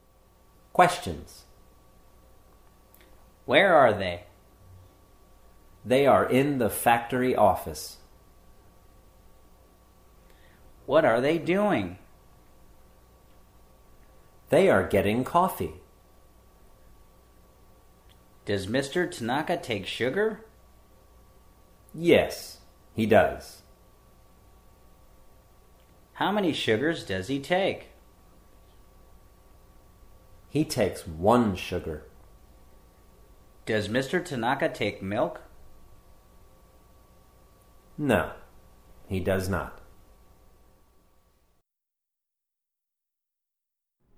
A business English dialog series (with questions and answers for beginning level learners)